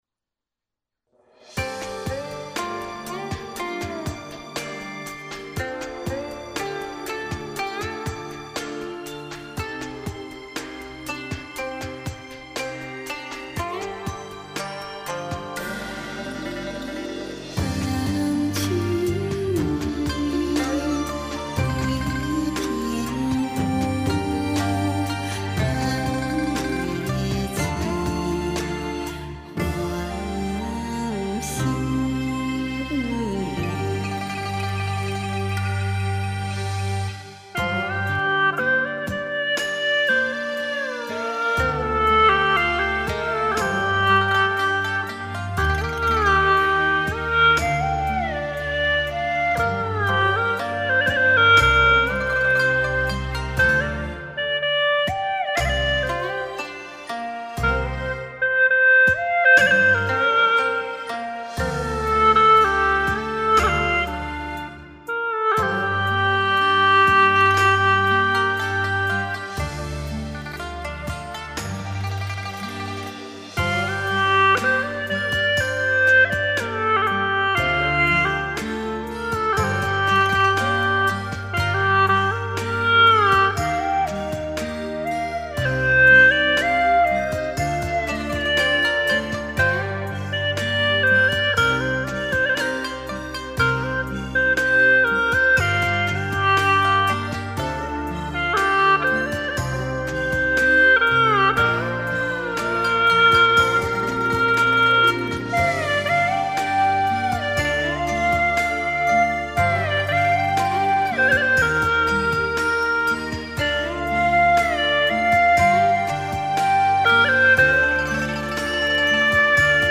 调式 : 降B 曲类 : 独奏